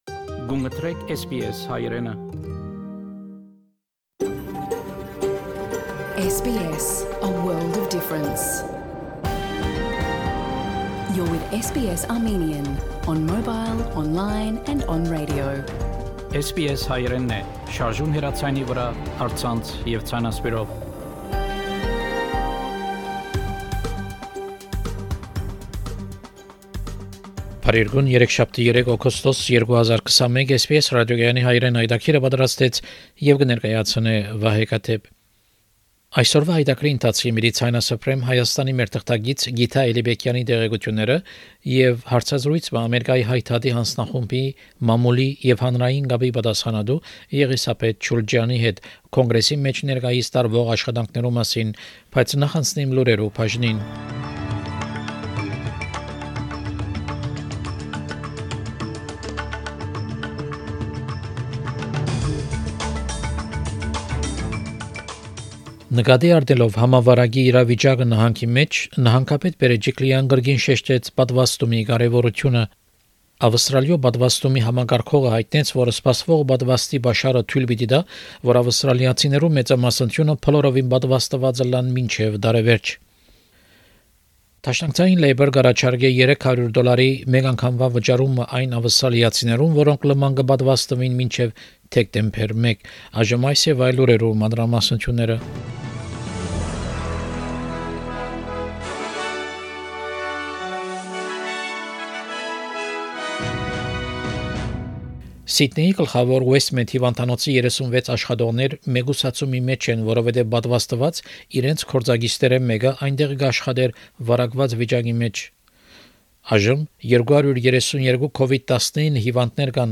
SBS Armenian news bulletin – 3 August 2021
SBS Armenian news bulletin from 3 August 2021 program.